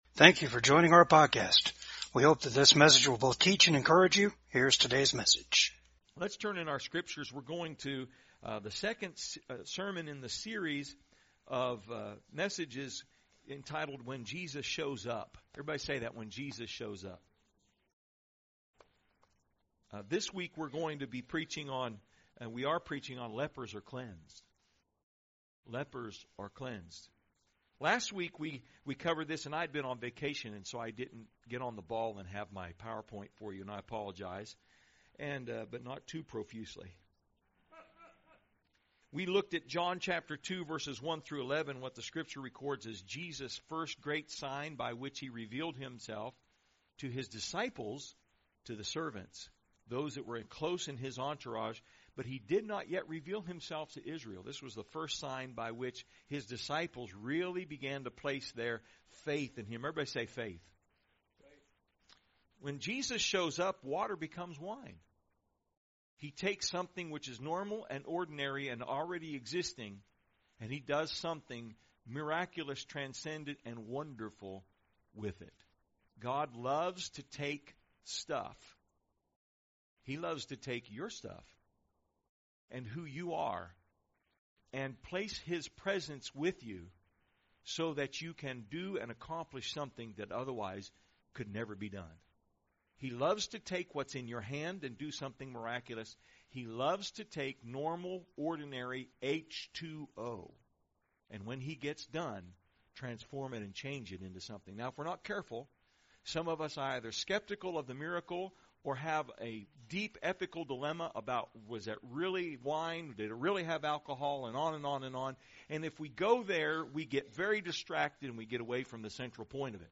Service Type: VCAG SUNDAY SERVICE